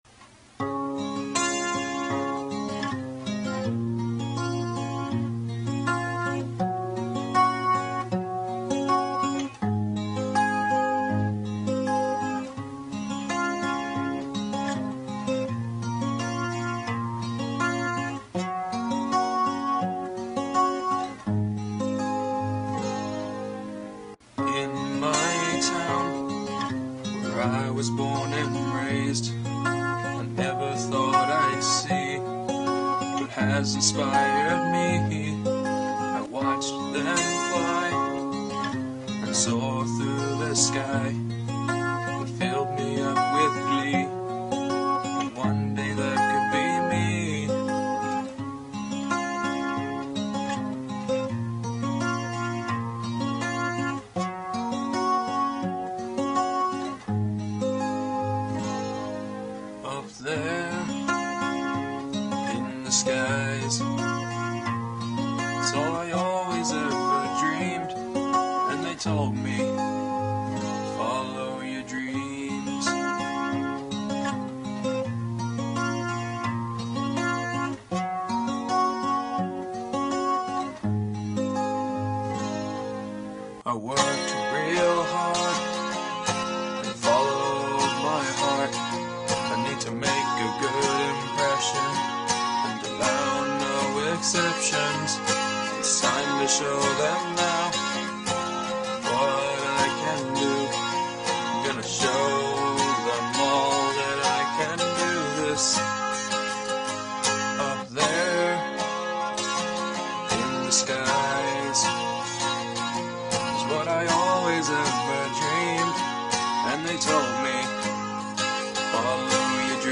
And this one has a solo.